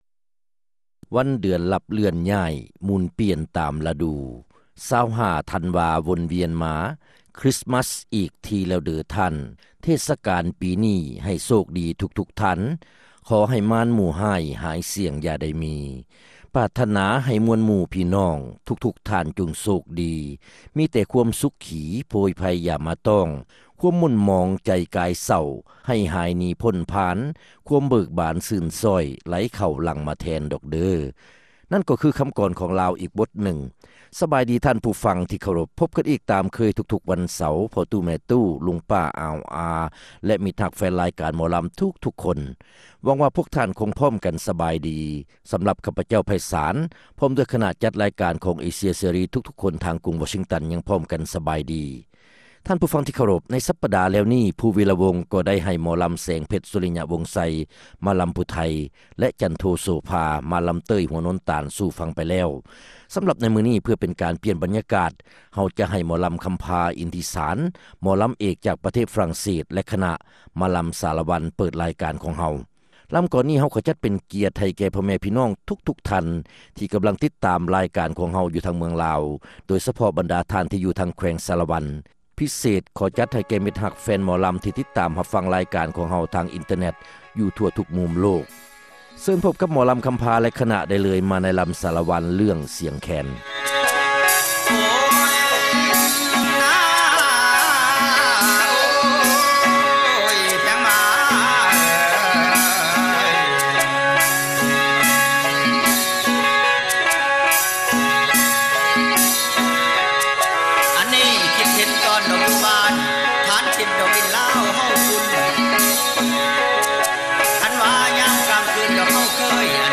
ຣາຍການໜໍລຳ ປະຈຳສັປະດາ ວັນທີ 23 ເດືອນ ທັນວາ ປີ 2005